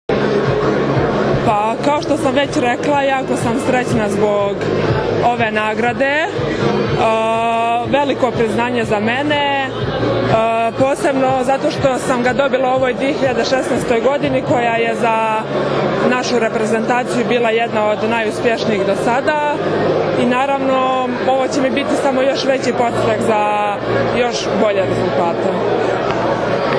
U beogradskom hotelu Metropol danas je održan tradicionalni Novogodišnji koktel Odbojkaškog saveza Srbije, na kojem su podeljenje nagrade najboljim pojedincima i trofeji “Odbojka spaja”.